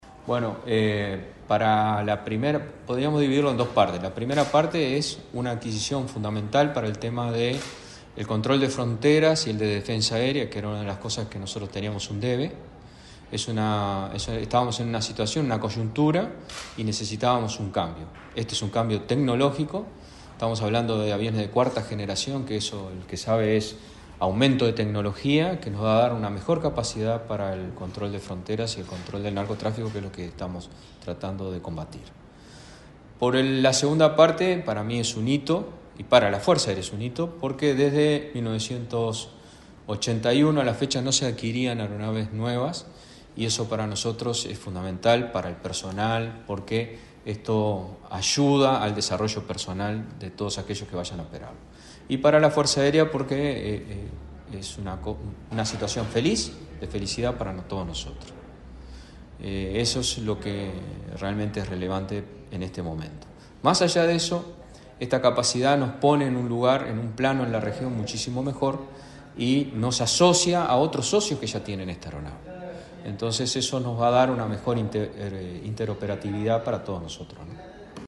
Declaraciones del comandante en Jefe de la Fuerza Aérea, Luis de León